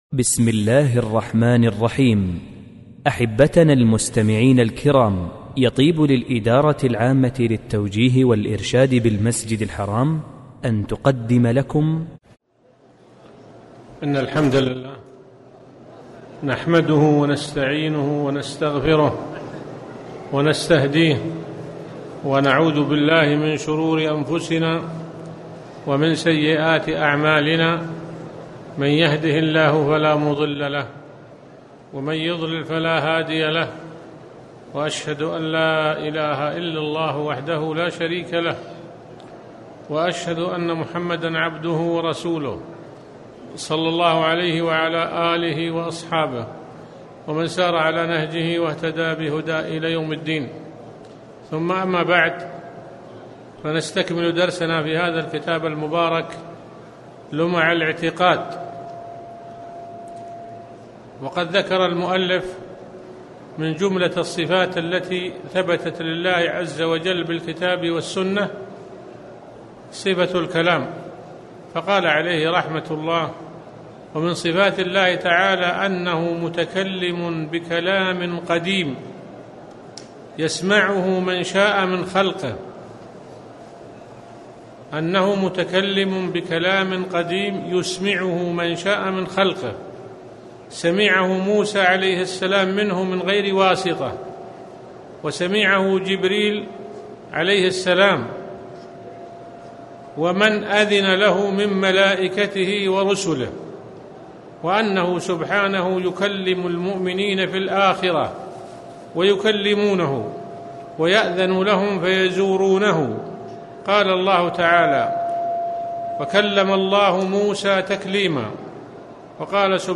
تاريخ النشر ١٨ صفر ١٤٣٩ المكان: المسجد الحرام الشيخ